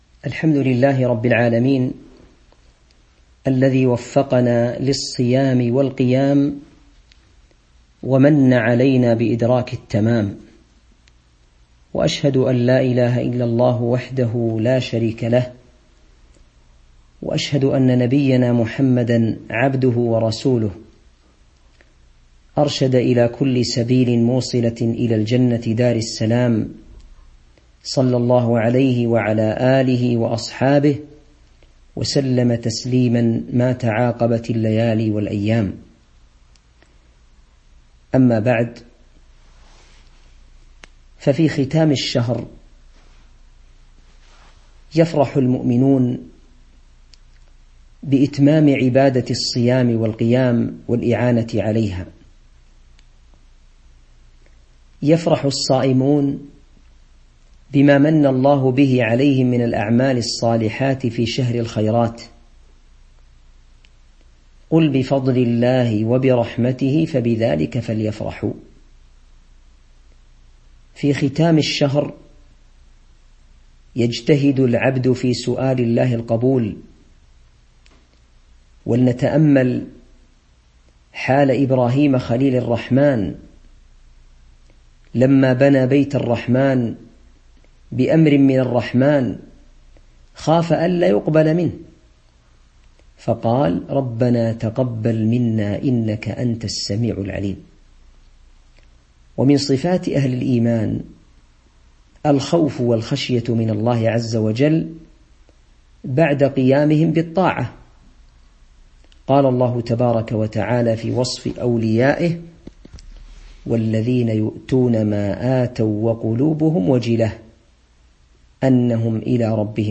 تاريخ النشر ٣٠ رمضان ١٤٤٣ هـ المكان: المسجد النبوي الشيخ